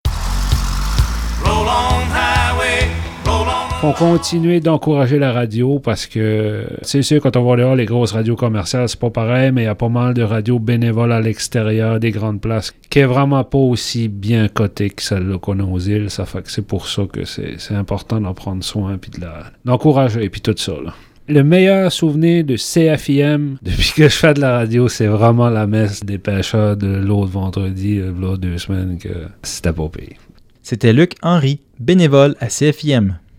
Dans le cadre du 44e Radiothon, les animateurs et animatrices bénévoles du Son de la mer ont été invités à témoigner de leur lien avec la radio communautaire des Îles et de que ça représente pour eux.